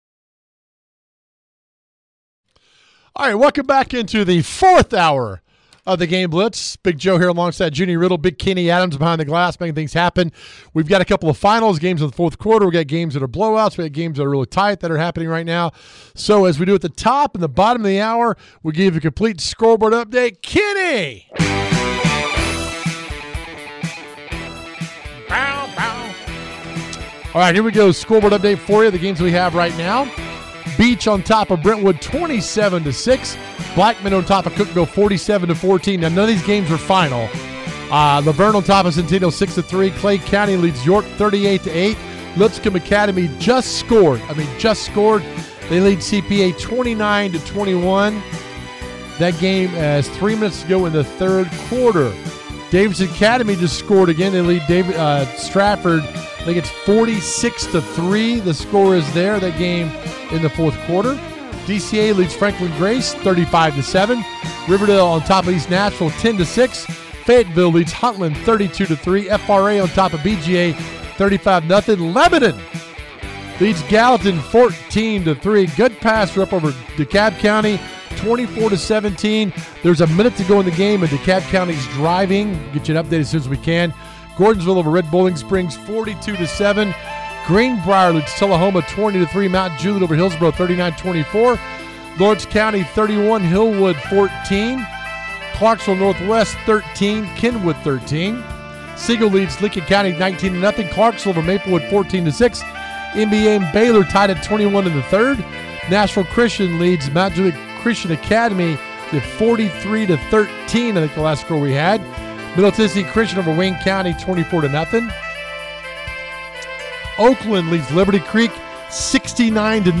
We have reporters on location along with coaches interviews!